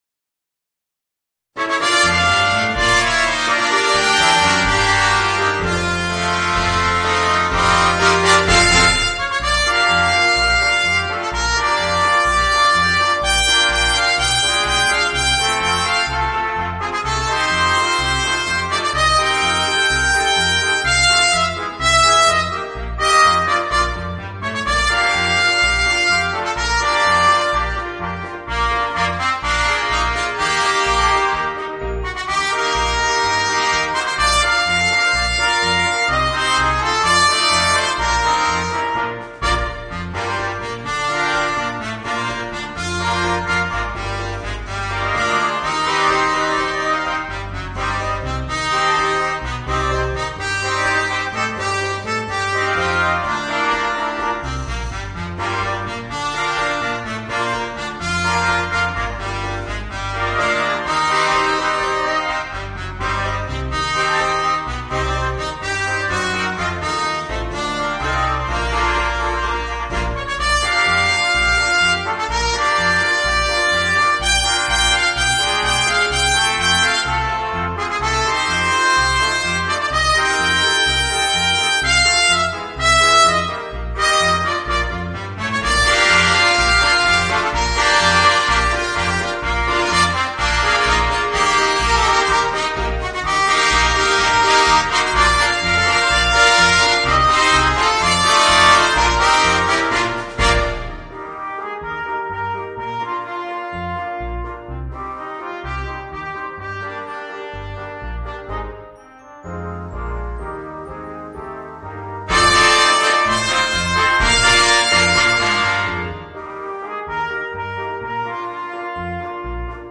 Voicing: 5 Trumpets